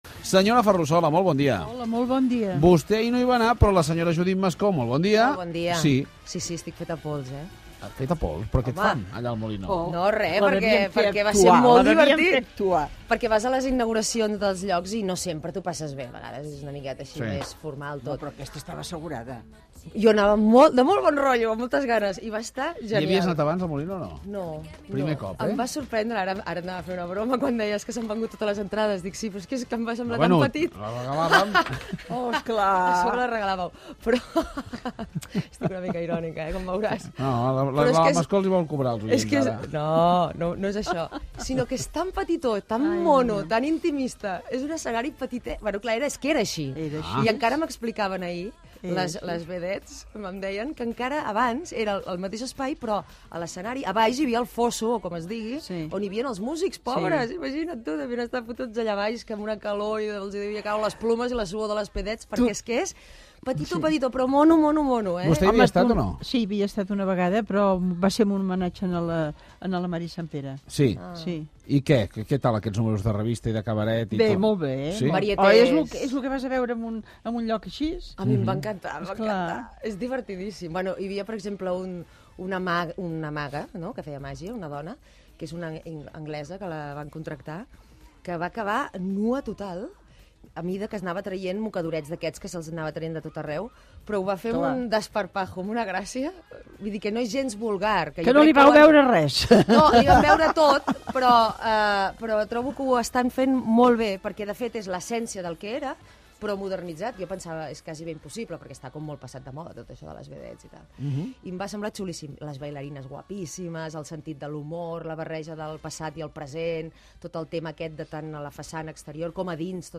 Les col·laboradores, Marta Ferrusola i Judit Mascó, parlen de la reobertura del teatre musical El Molino al Paral·lel de Barcelona i de la integració de la immigració a Alemanya Gènere radiofònic Info-entreteniment Presentador/a Fuentes, Manel